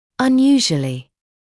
[ʌn’juːʒuəlɪ][ан’юːжуэли]необычно; нетипично